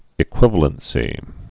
(ĭ-kwĭvə-lən-sē)